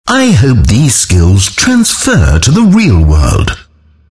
dlc_stanleyparable_killing_spree_announcer_kill_holy_08.mp3